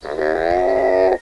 Звуки ламы